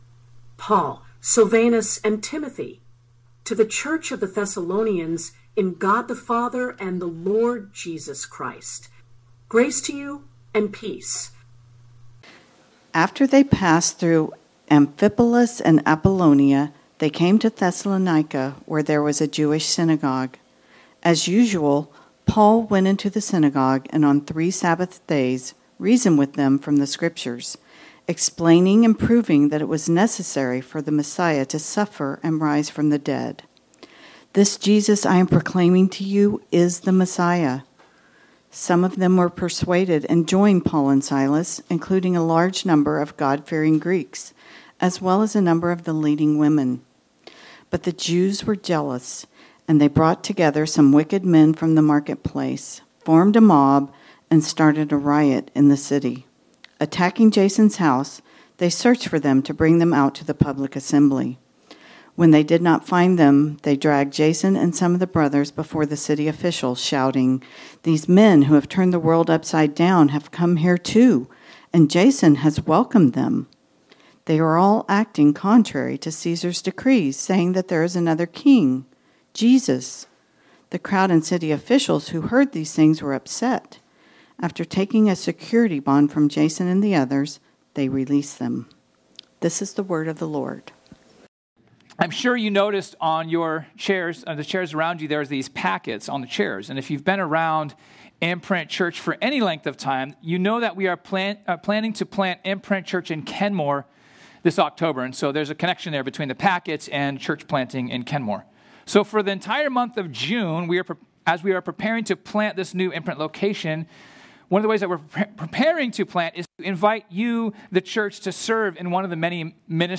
This sermon was originally preached on Sunday, June 1 2025.